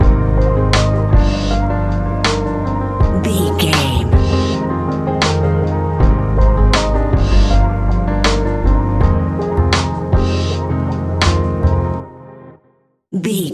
Ionian/Major
F♯
chilled
laid back
Lounge
sparse
new age
chilled electronica
ambient
atmospheric
instrumentals